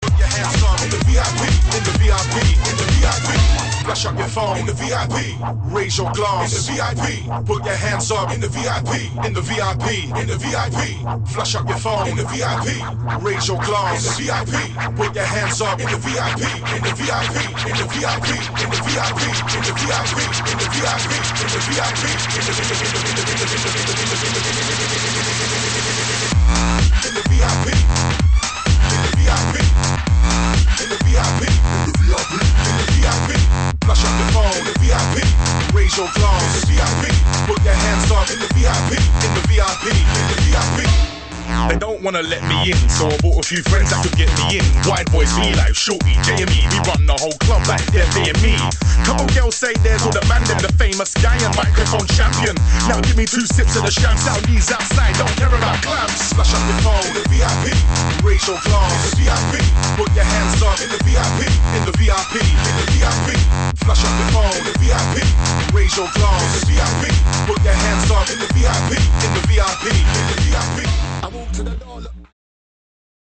[ HOUSE | UKG | DUBSTEP ]